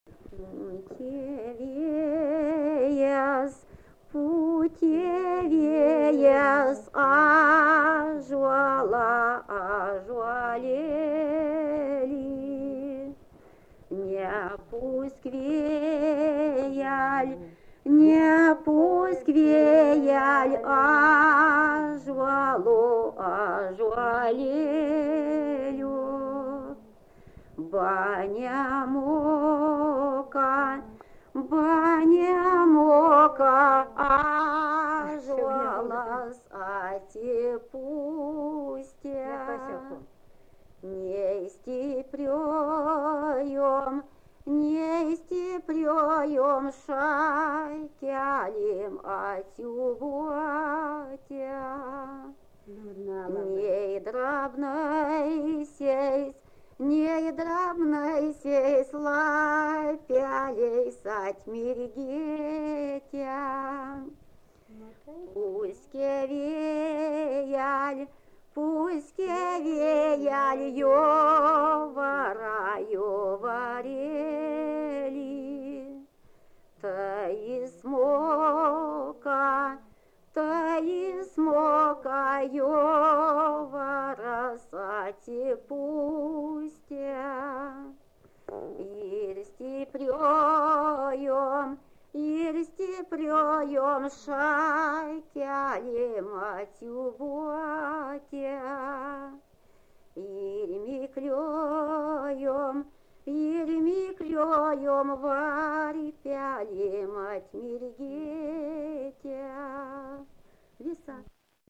Dalykas, tema daina
Erdvinė aprėptis Trečionys
Atlikimo pubūdis vokalinis